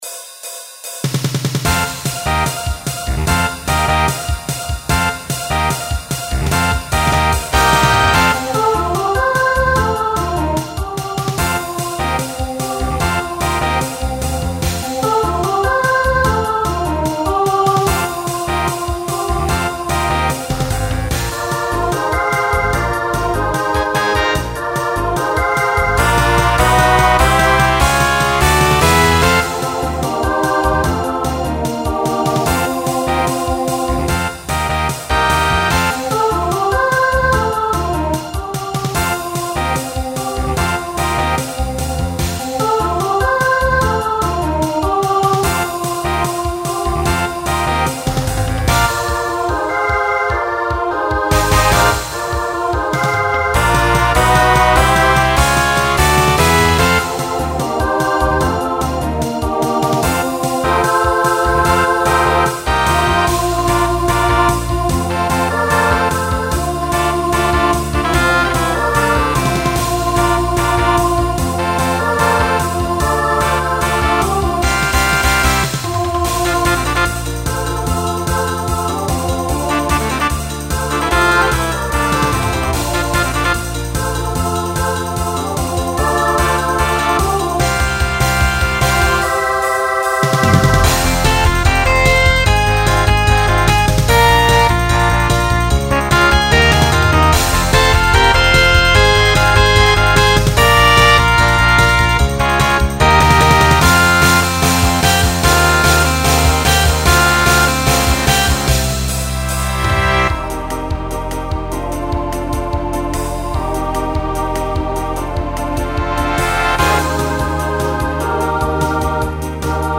Genre Broadway/Film , Pop/Dance Instrumental combo
Voicing SSA